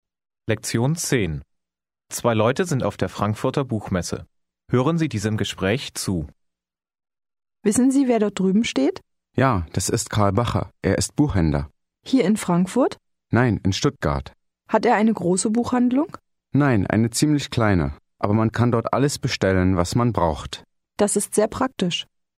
Аудио курс для самостоятельного изучения немецкого языка.